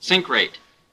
sink-rate.wav